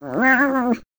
sounds / monsters / cat